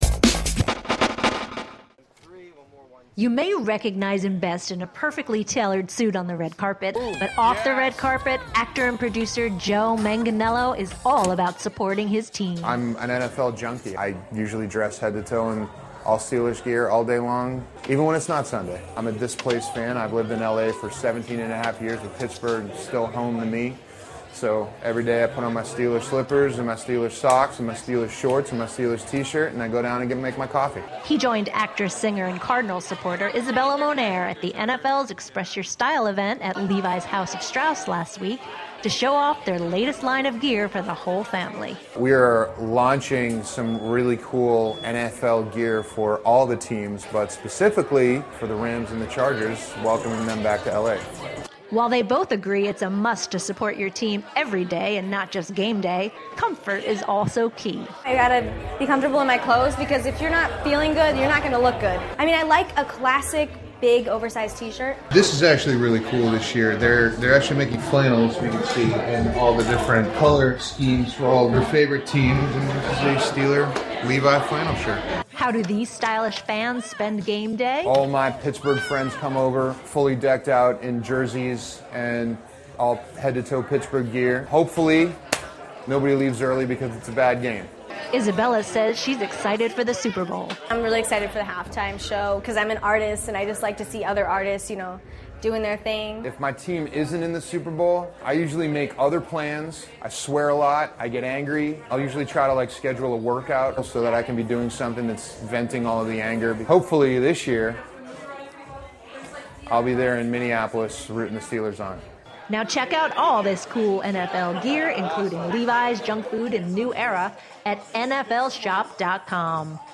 The stars showed off their favorite gear at the NFL Express Your Style Event at the Levi’s Haus of Strauss in Los Angeles